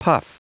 Prononciation du mot puff en anglais (fichier audio)
Prononciation du mot : puff